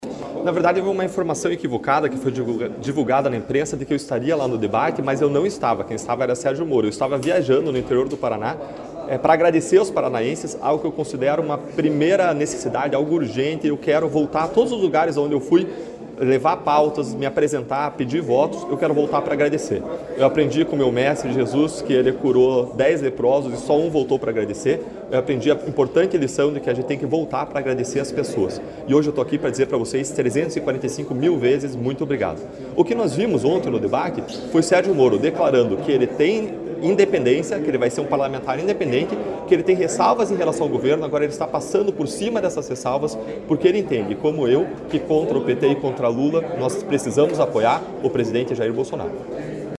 Player Ouça Deltan Dallagnol